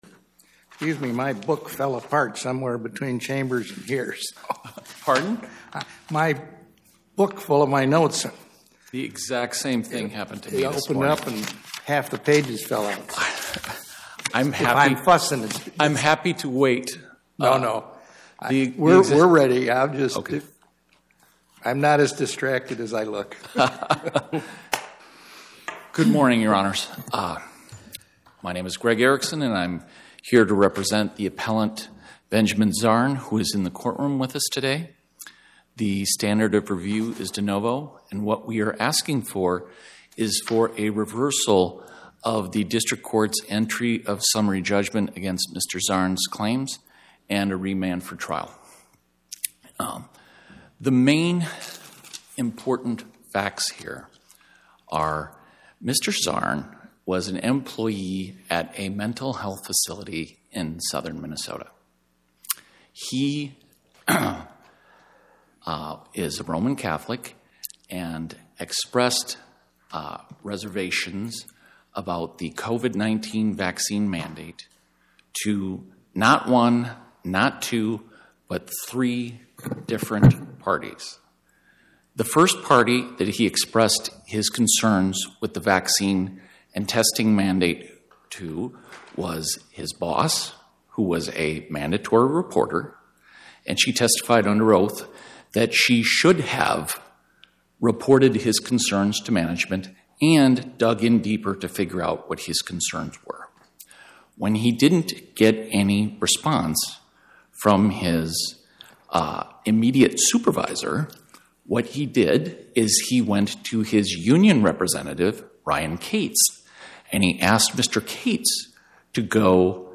Dept. of Human Services Podcast: Oral Arguments from the Eighth Circuit U.S. Court of Appeals Published On: Thu Oct 23 2025 Description: Oral argument argued before the Eighth Circuit U.S. Court of Appeals on or about 10/23/2025